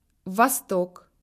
Consonnes
Écoutez la différence en russe sur ces quelques exemples:
"DUR"